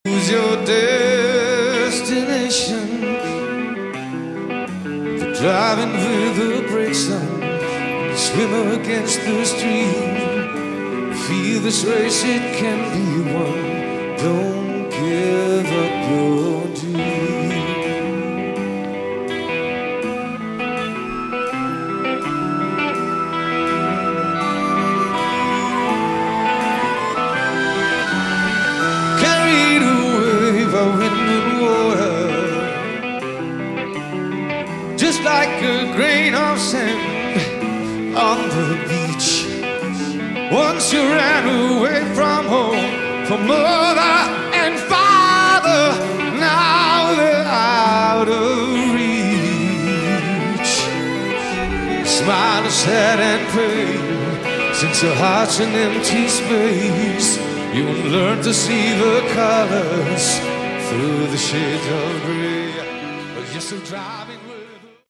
Category: Melodic Rock
vocals, guitar
bass
drums